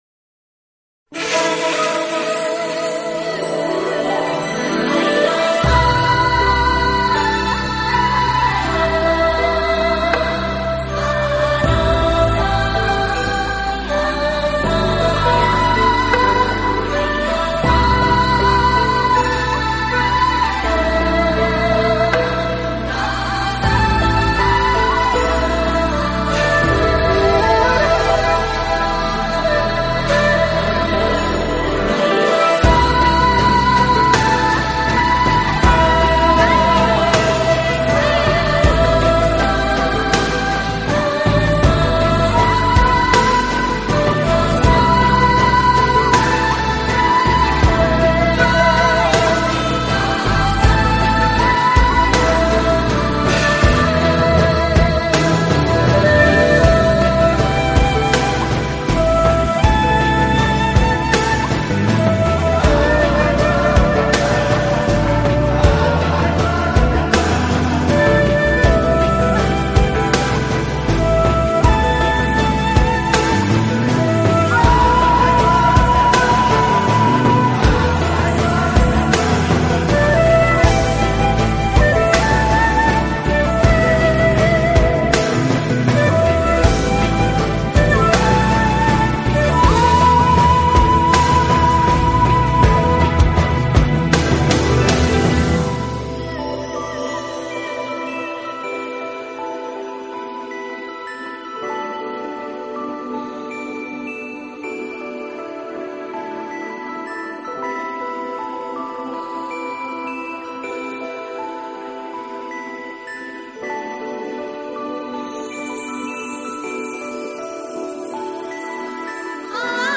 尺八 & 笛
Voice
琴
Guitar
二胡
Keyboard & Programming
亚洲风韵为主题的New Age专辑